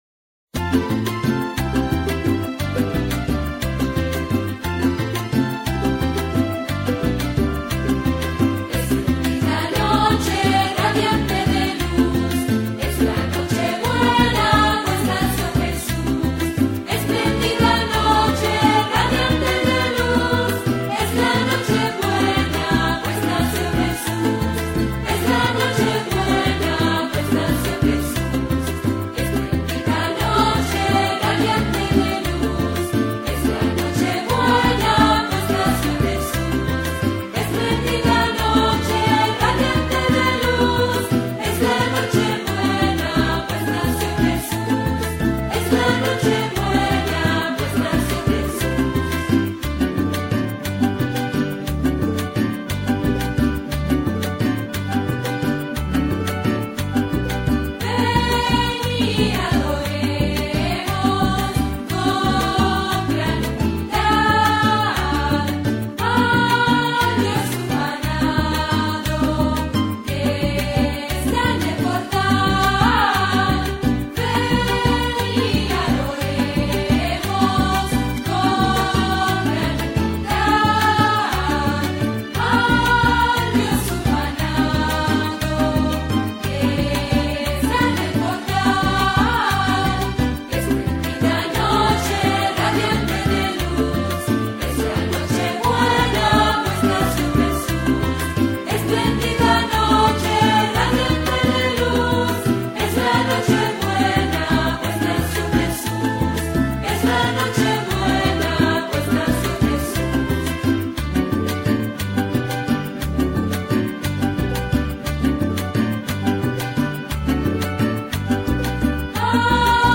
Volver a Podcast Ver todo espléndida noche on 2008-12-25 - Villancicos Descargar Otros archivos en esta entrada Nuestras Creencias Las creencias adventistas tienen el propósito de impregnar toda la vida.